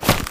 Footstep5.wav